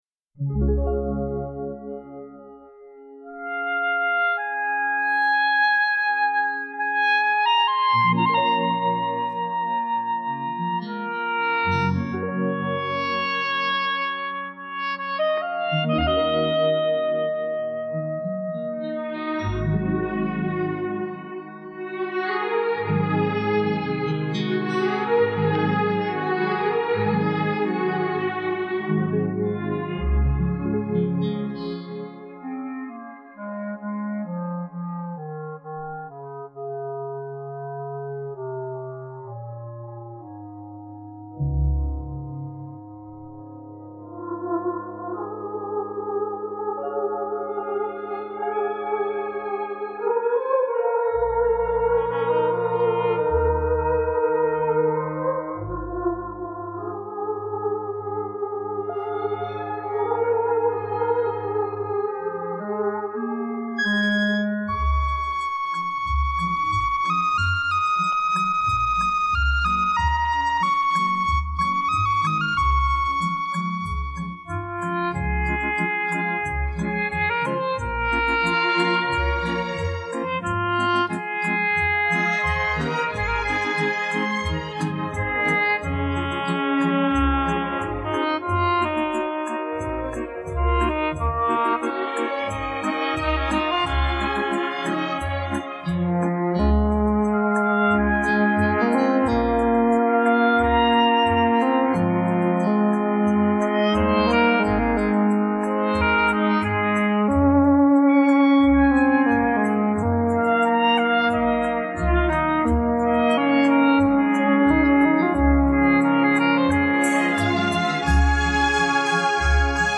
不朽的古典小品（之四）